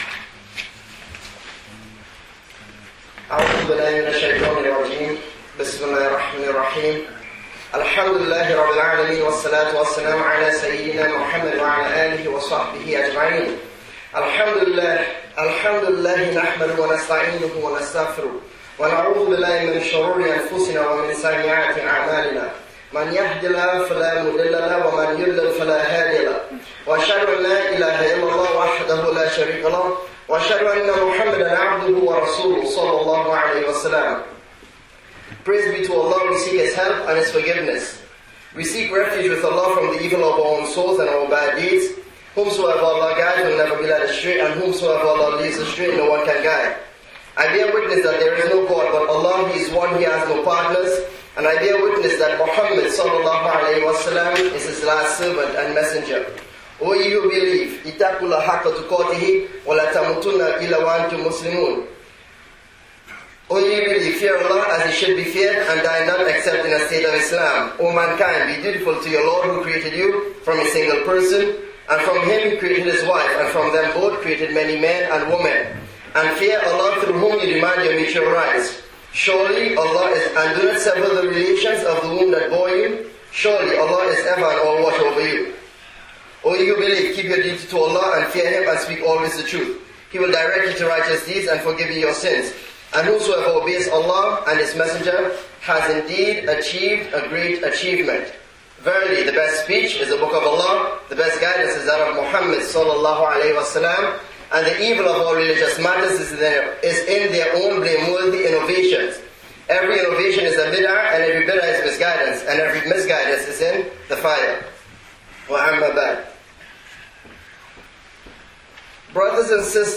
(If above player does not work, click " Advice: Being Concerned for your Brother's/Sister's Soul " for audio recording of the khutbah) -...